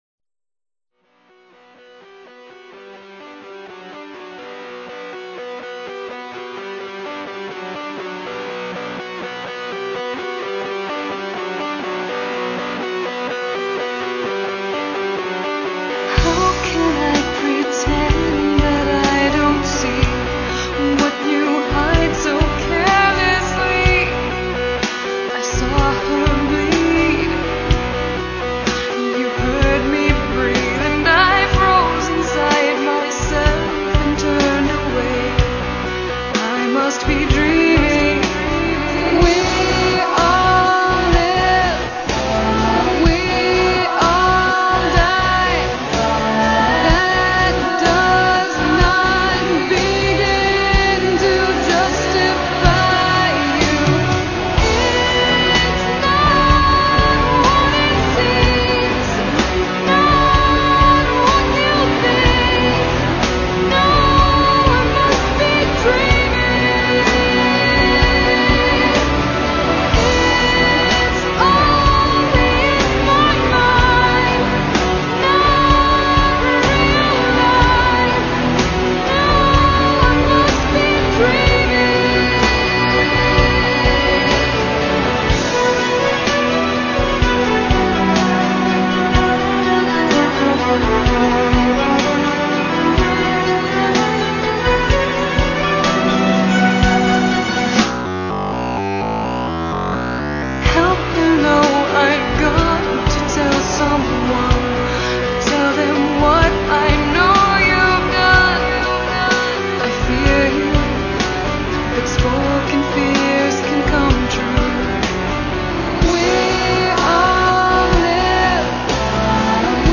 This song was recorded during the 2001-2002 demo sessions.